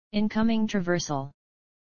Tag: 声乐 清唱 声音 口语